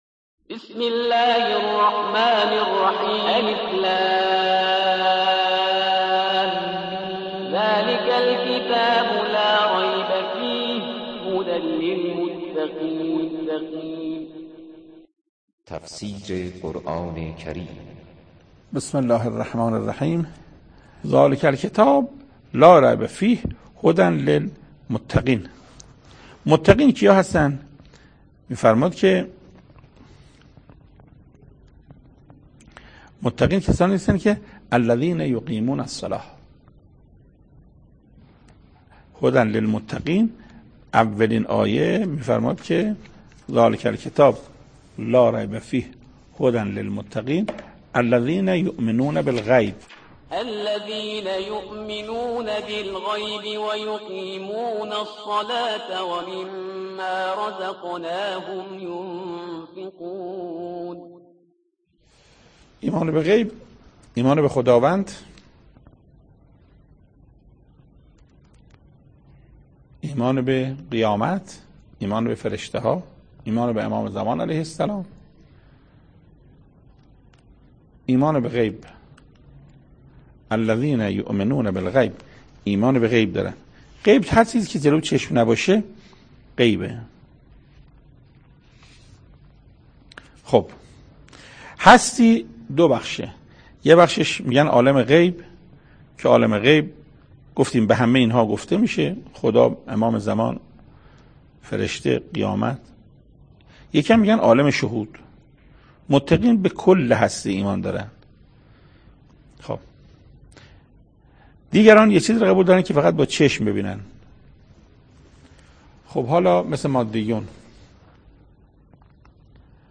تفسیر سومین آیه از سوره مبارکه بقره توسط حجت الاسلام استاد محسن قرائتی به مدت 21 دقیقه